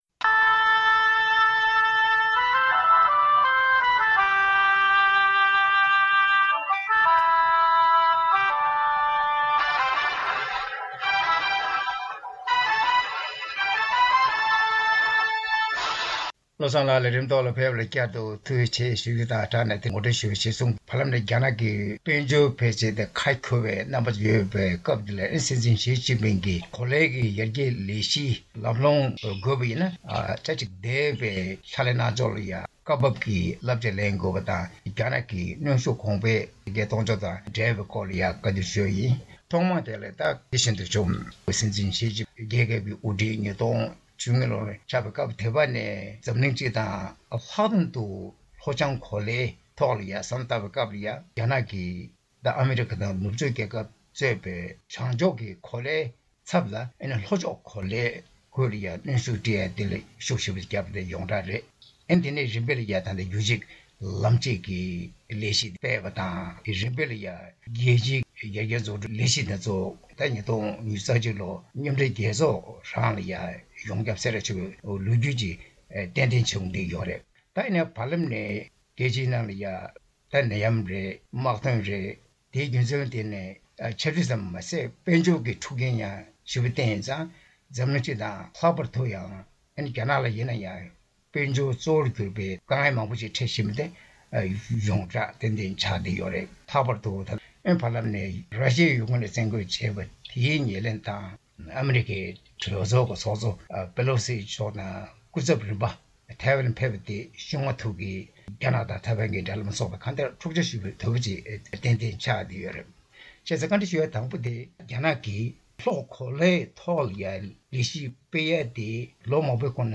གླེང་མོལ་ཞུས་པ་ཞིག་གསན་རོགས་ཞུ།།